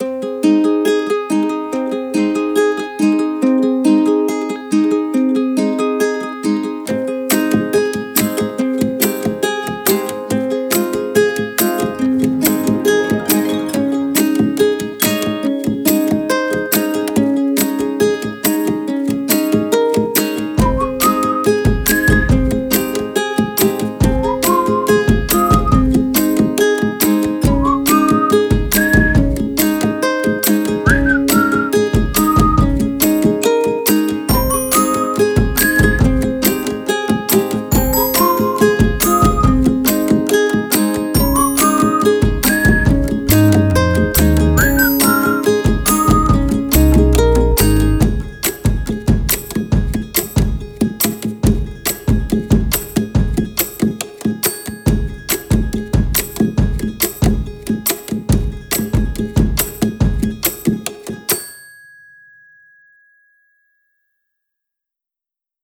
HAPPY UKULELE
Positive / Easy / Acoustic / Chill